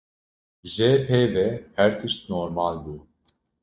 Pronounced as (IPA) /peː/